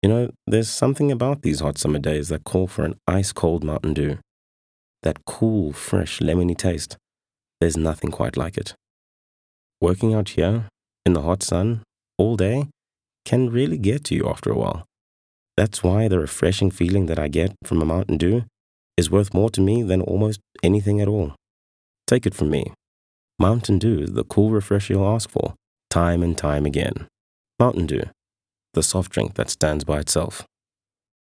balanced, neutral, unaccented
My demo reels